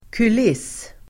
Uttal: [kul'is:]